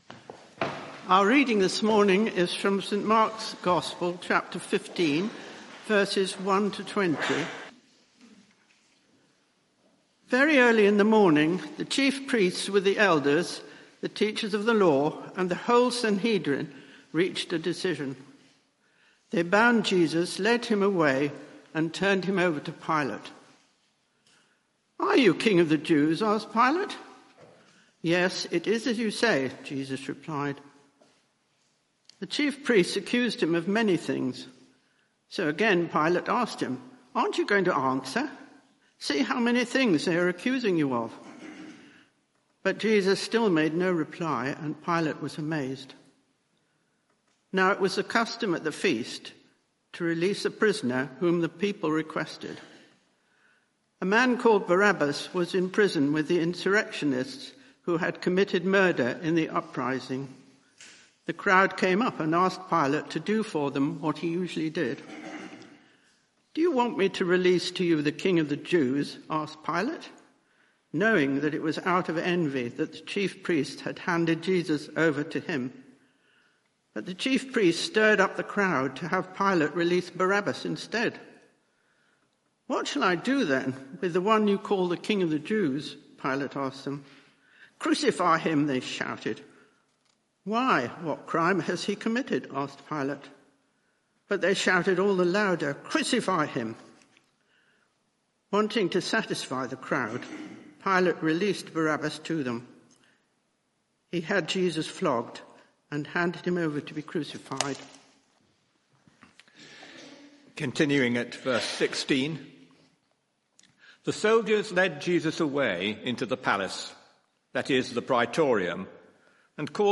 Media for 11am Service on Sun 17th Mar 2024 11:00 Speaker
Series: The Road to Glory Theme: God Save the King? Sermon (audio) Search the media library There are recordings here going back several years.